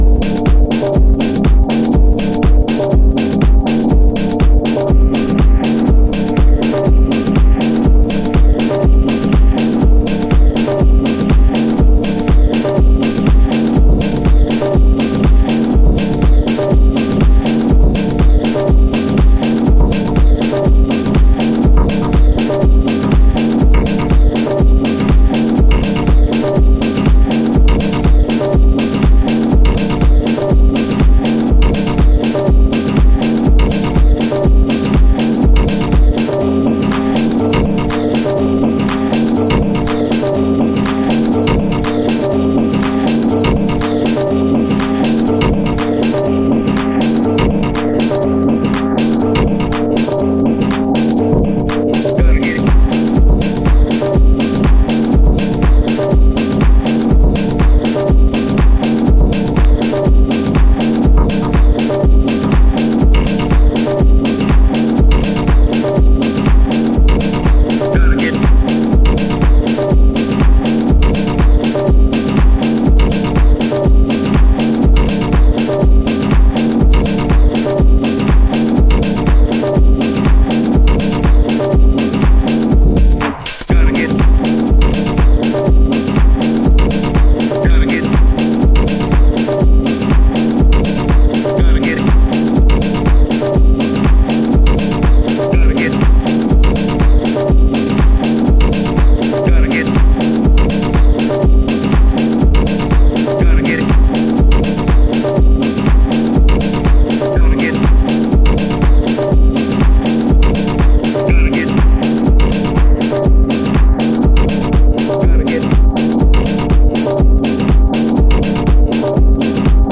極度に歪んだ音像にとメロウなシンセワーク、ファンクネス溢れるドラムプログラミングが勇ましいニュースクールハウス。